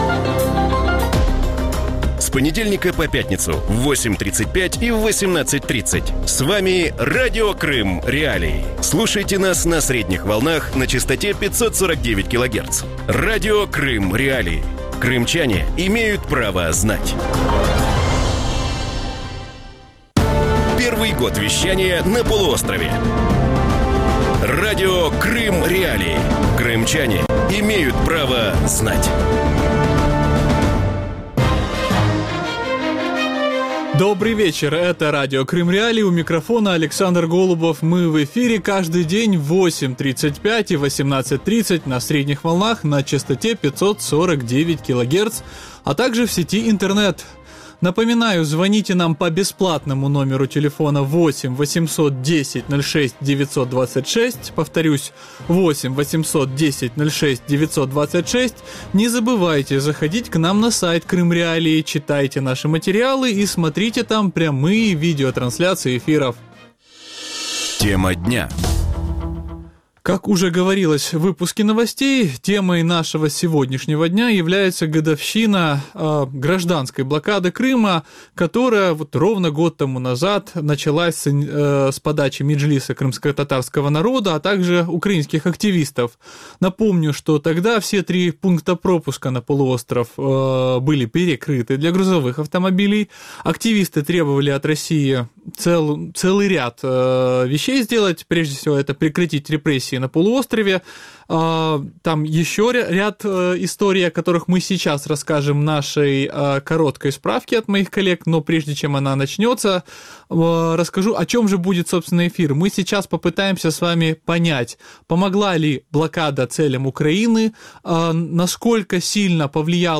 У вечірньому ефірі Радіо Крим.Реалії обговорюють річницю початку громадянської блокади Криму. Які плоди вона принесла, скільки втратив Крим і материкова Україна від заборони вантажоперевезень і чи є подальші плани щодо деокупації півострова?